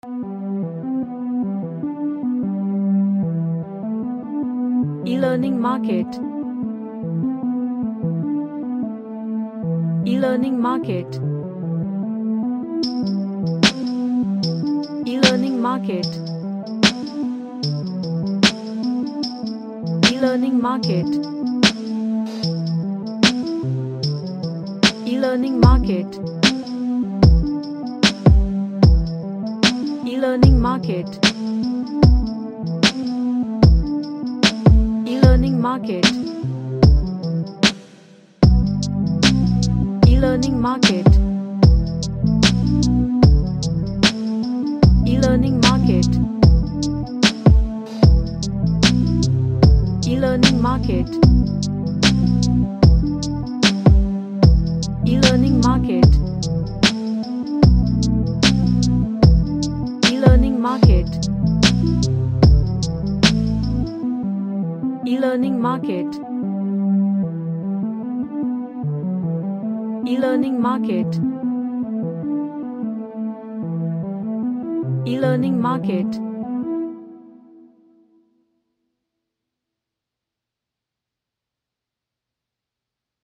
A chill and gentle R&B track
Gentle / LightChill Out